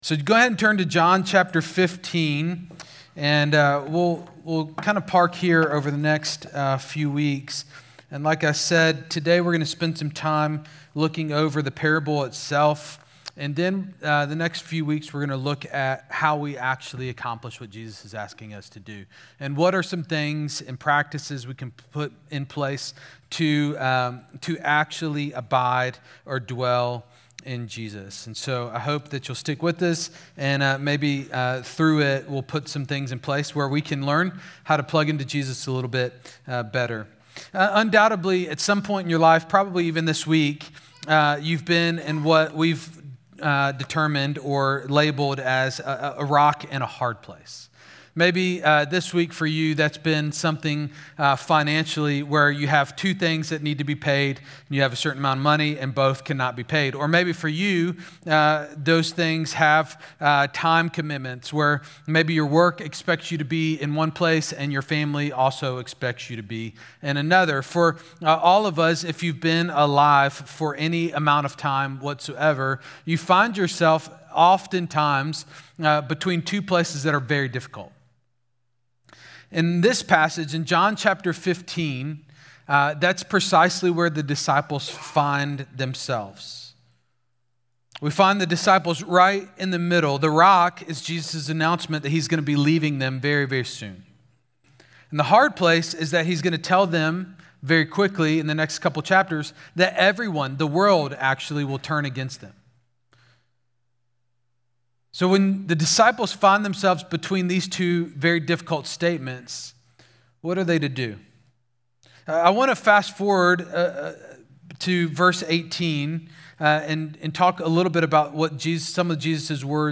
Exchange Church Sermons